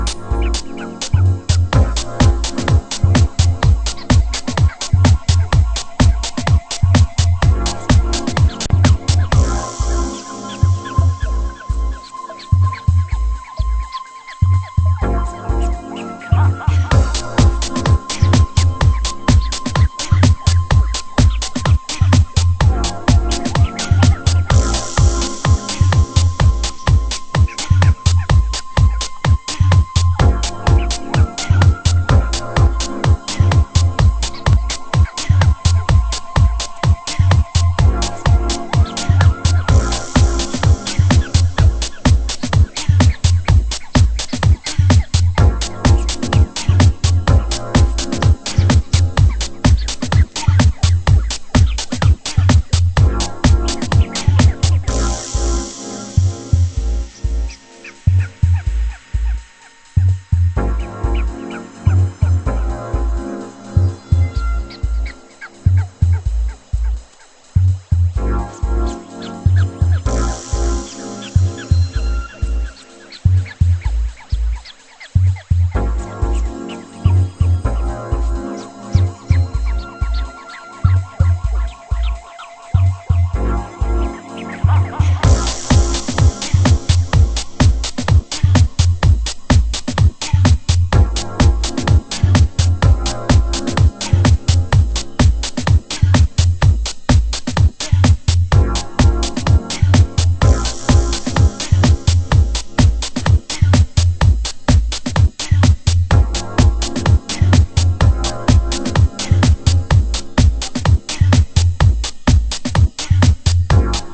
Vocal Dub